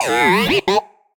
happy2.ogg